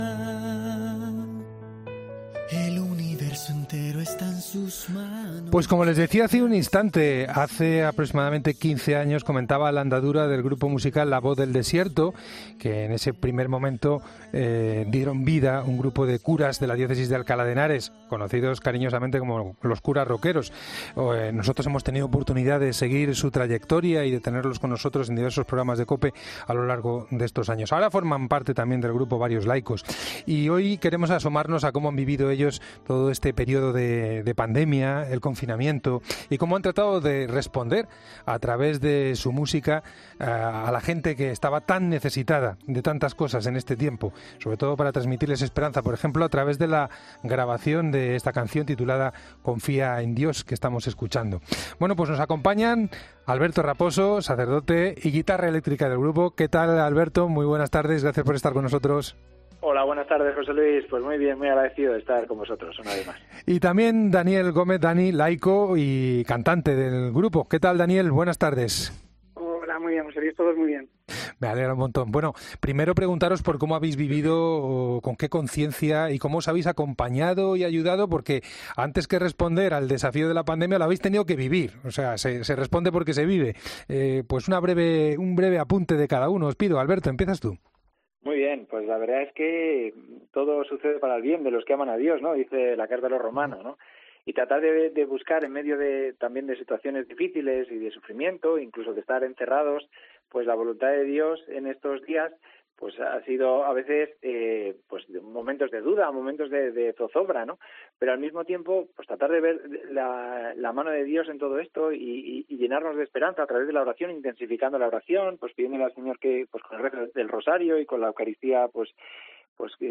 El 'grupo de curas' de Alcalá de Henares -que ahora también está compuesto por laicos- se han pasado por El Espejo para contar cómo han vivido estos meses, con la intención siempre de transmitir esperanza a todos los que les siguen.